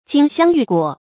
金镶玉裹 jīn xiāng yù guǒ
金镶玉裹发音